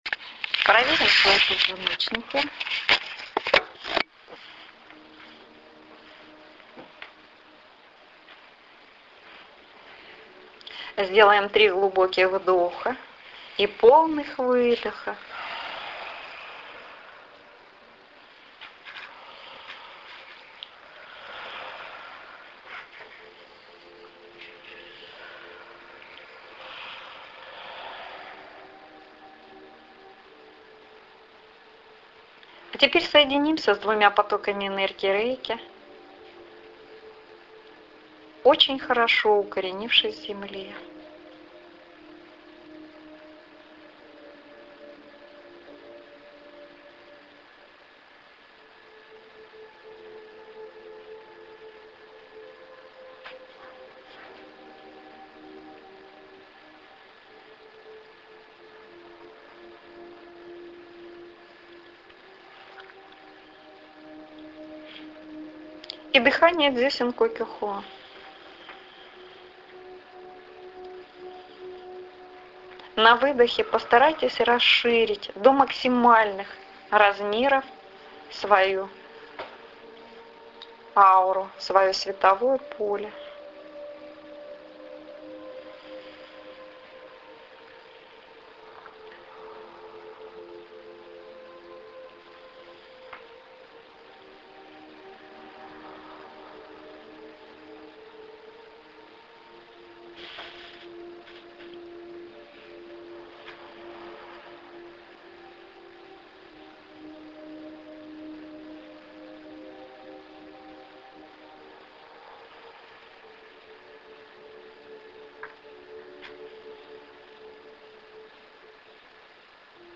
Радужный Мост (аудио-медитация) Цель этой медитация состоит в том, чтобы действительно проявить Радужный Мост Земли к моменту зимнего (в Северном полушарии), или летнего (в Южном), солнцестояния 2012 года. Радужный Мост - это Мост Мира, о котором издревле говорили пророчества.